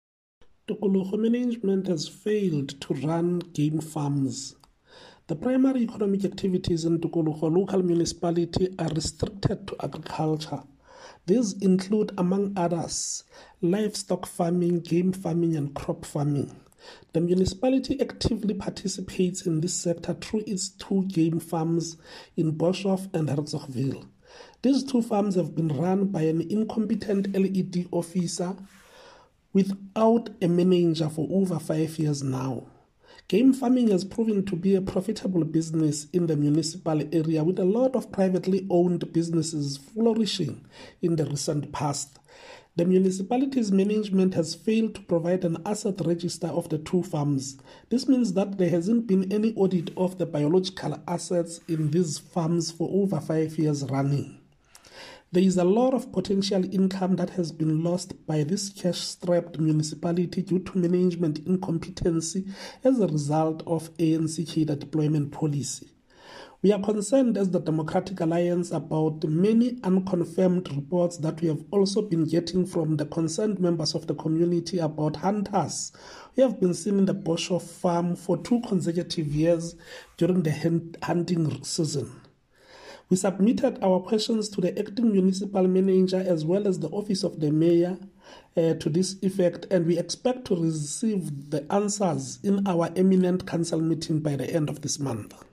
Sesotho soundbites by Cllr Hismajesty Maqhubu.
Eng-voice-27.mp3